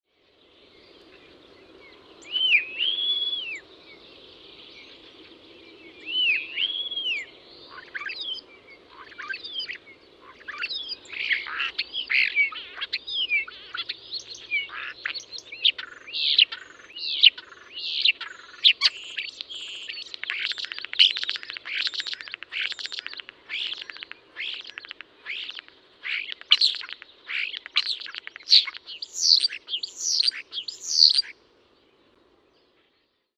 Kottarainen on hyvä matkija, jonka laulusta erottaa monien muiden lintujen ja jopa koneiden ääniä.